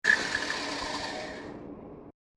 These sounds are primarily industrial / noise type sounds.
The following is a series of very short samples.
sound 4 2 sec. mono 25k